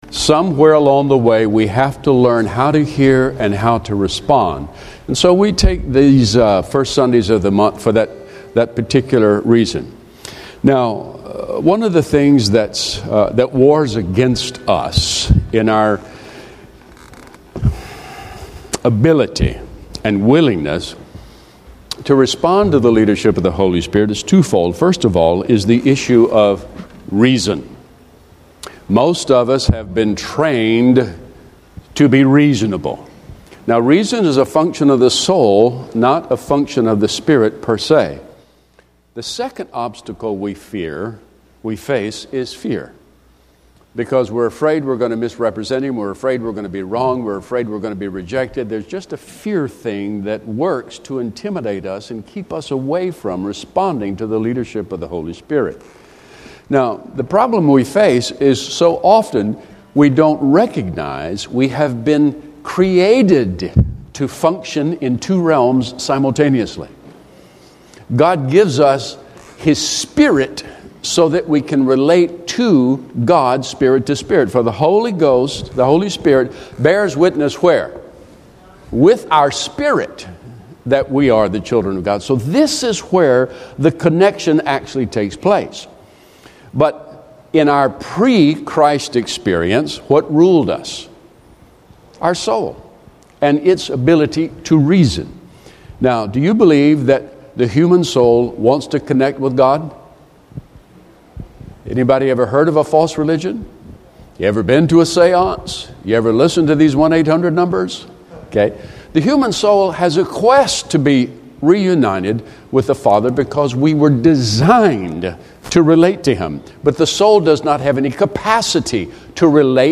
(Video recording started several minutes into the service.)